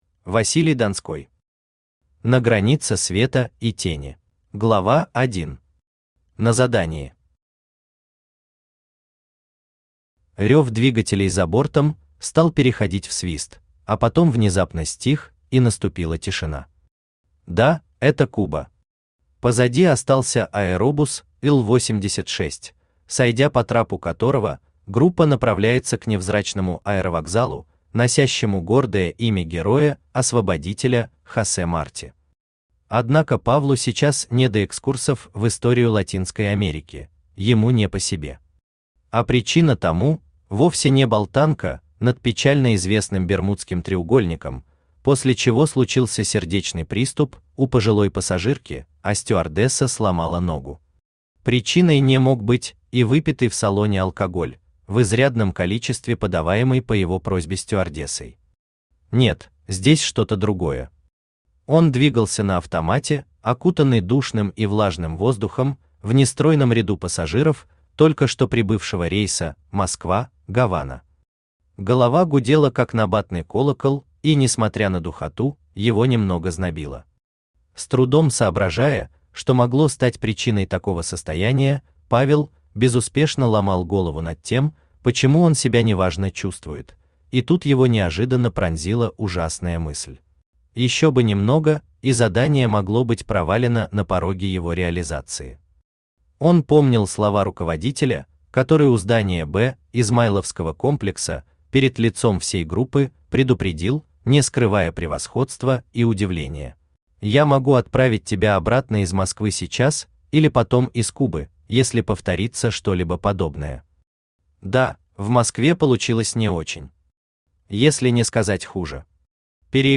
Аудиокнига На границе света и тени | Библиотека аудиокниг
Aудиокнига На границе света и тени Автор Василий Донской Читает аудиокнигу Авточтец ЛитРес.